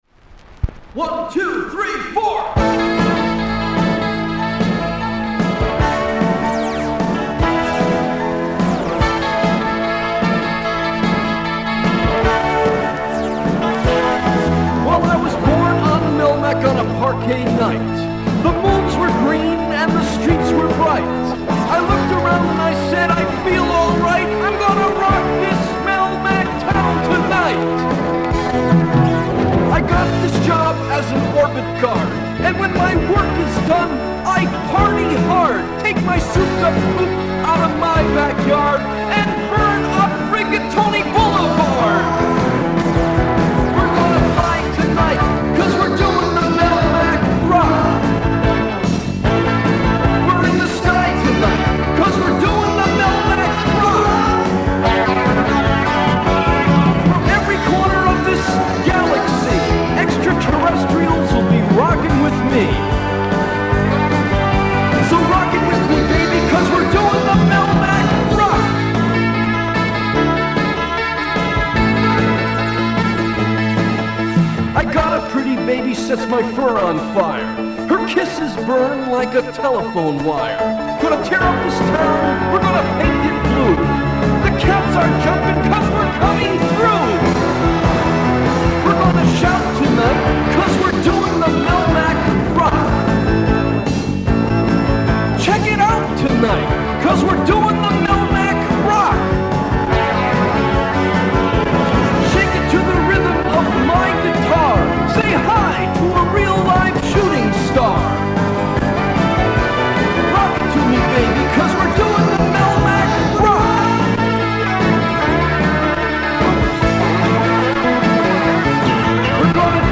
the guitars are front and center